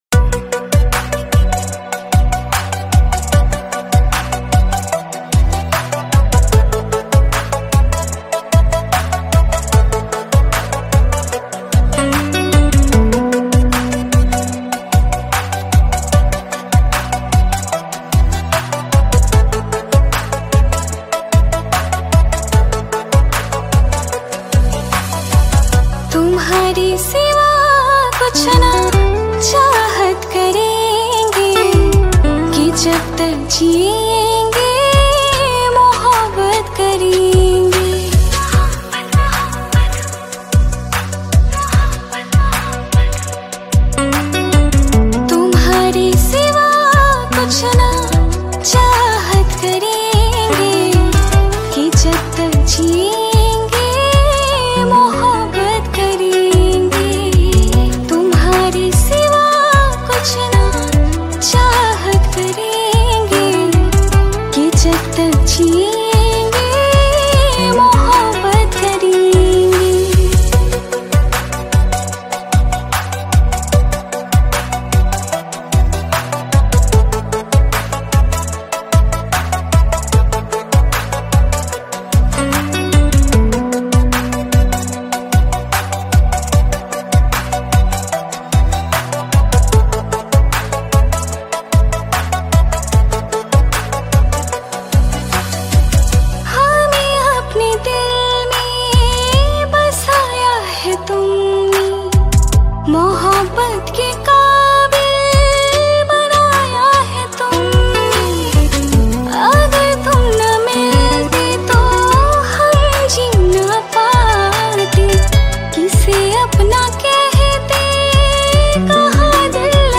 Bollywood Cover Songs